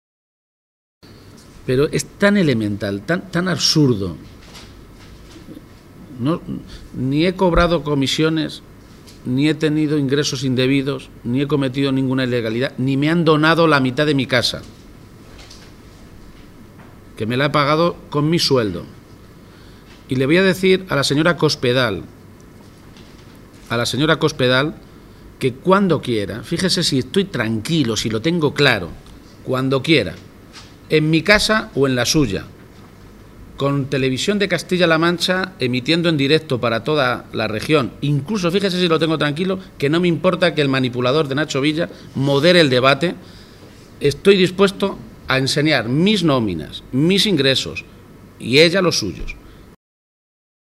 García-Page, que compartió un desayuno informativo con los medios de comunicación en Cuenca, insistió una vez más en la necesidad de que la presidenta de Castilla-La Mancha, María Dolores de Cospedal, comparezca en las Cortes regionales tras las últimas informaciones desprendidas del denominado caso Bárcenas.